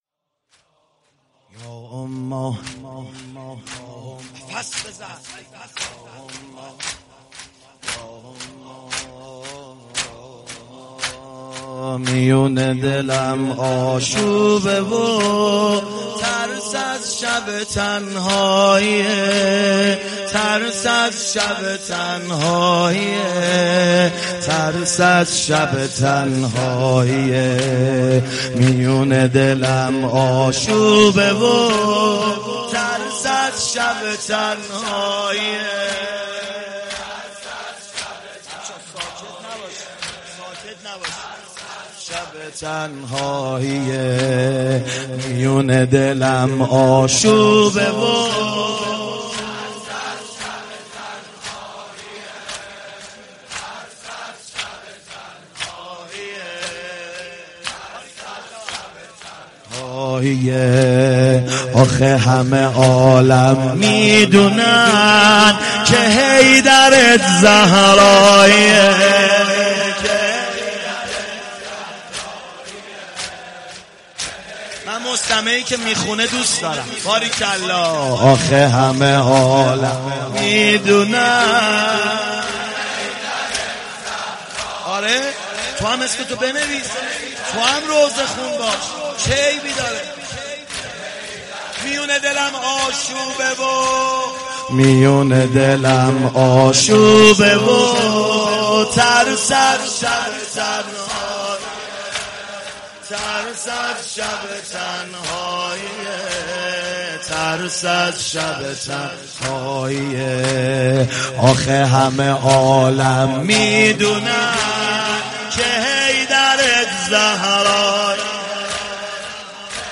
فاطمیه96 - ساوه - زمینه - میون دلم آشوبه و ترس از
فاطمیه